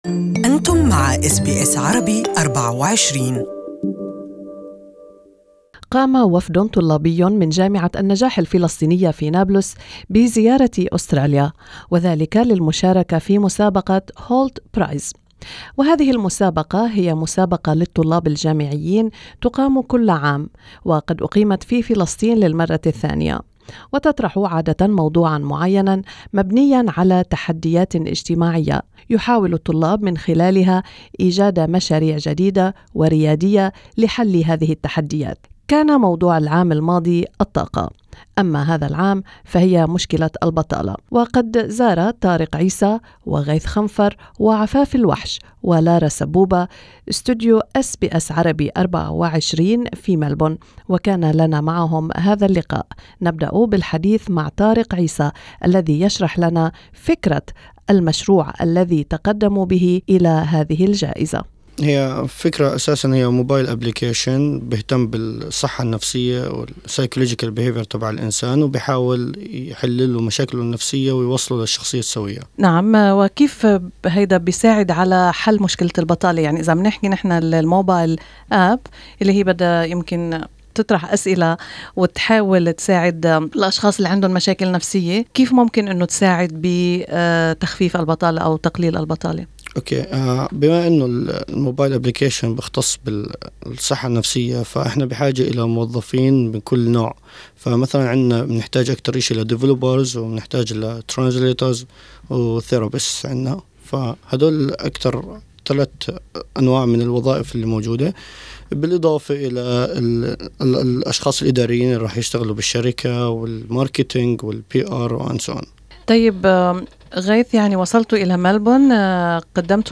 In an interview with SBS Arabic24 they spoke about their project and about their ambitions for the future.